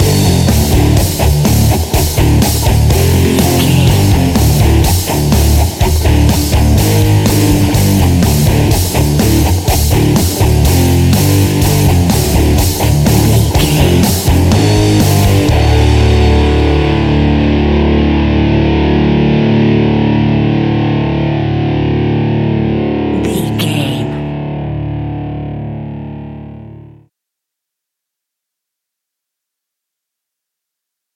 Ionian/Major
energetic
driving
heavy
aggressive
electric guitar
bass guitar
drums
electric organ
hard rock
heavy metal
distortion
distorted guitars
hammond organ